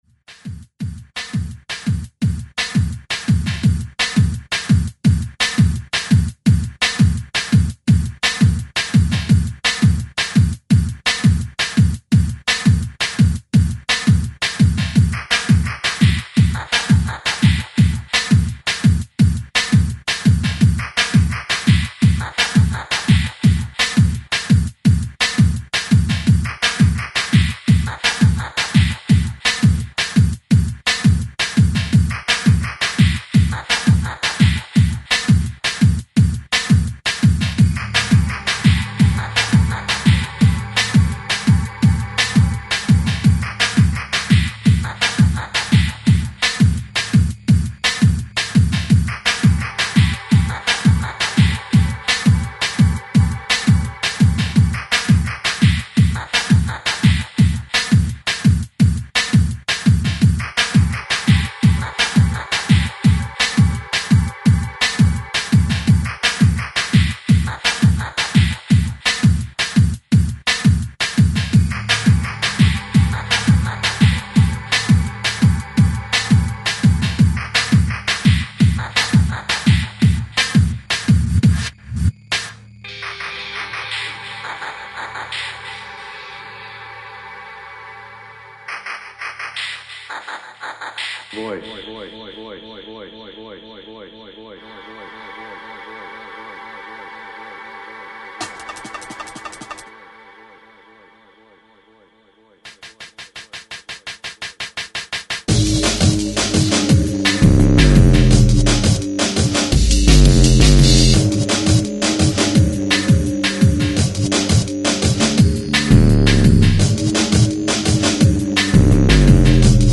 futuristic dark sounds
Techstep